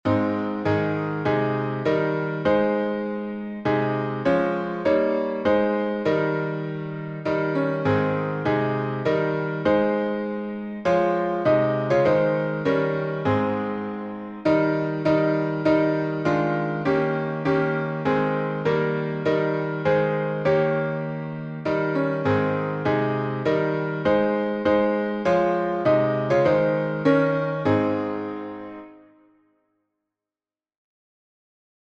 Legible, audible sheet music.
Key signature: A flat major (4 flats) Time signature: 3/4